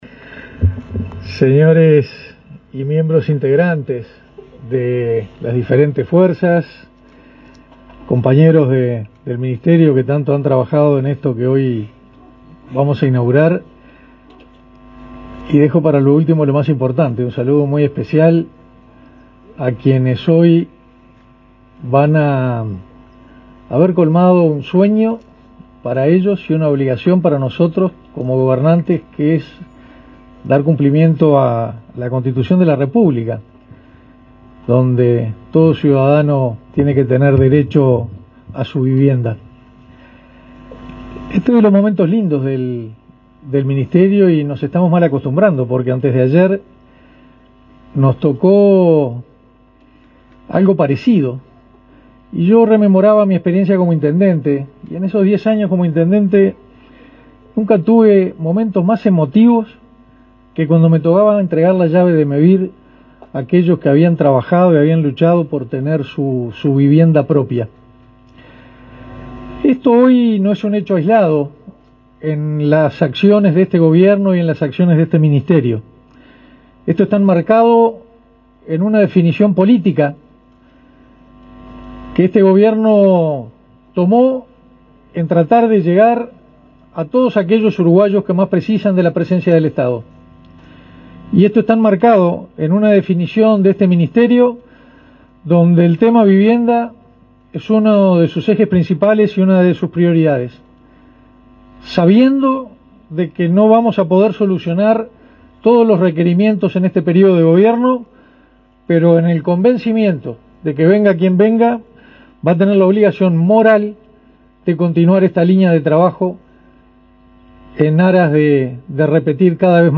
Palabras del ministro de Defensa Nacional, Armando Castaingdebat
Palabras del ministro de Defensa Nacional, Armando Castaingdebat 15/08/2024 Compartir Facebook X Copiar enlace WhatsApp LinkedIn El ministro de Defensa Nacional, Armando Castaingdebat, fue el orador central en el acto de entrega de viviendas para personal subalterno de las Fuerzas Armadas, ubicadas en el barrio Casavalle, en Montevideo.